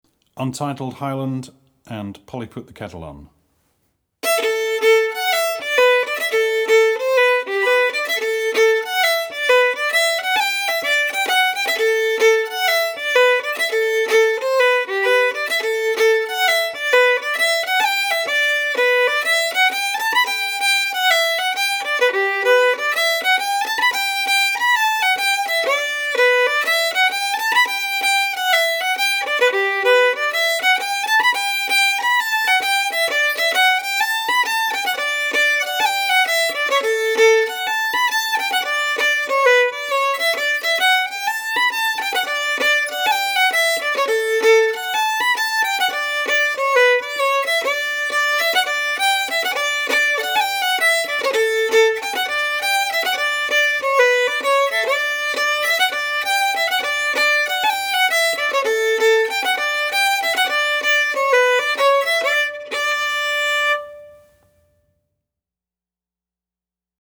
DIGITAL SHEET MUSIC - FIDDLE SOLO
Celtic/Irish